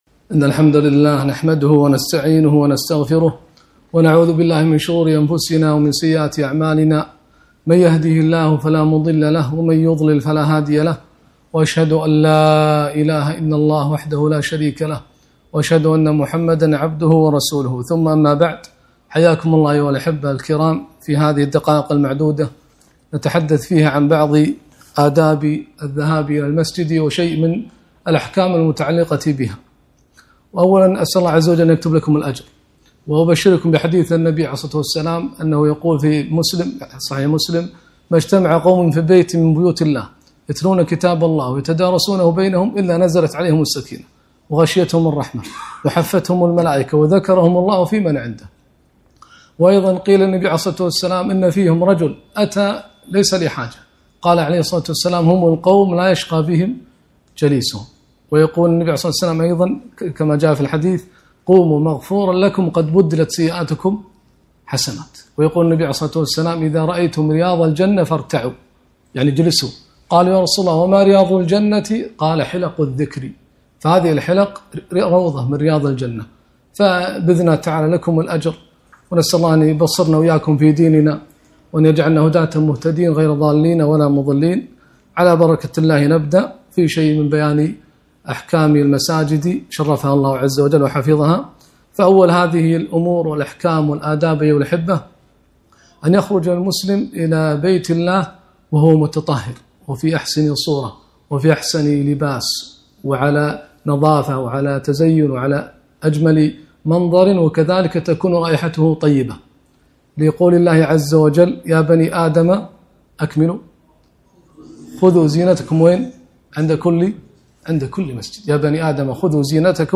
محاضرة - المشي إلى الصلاة أحكام وآداب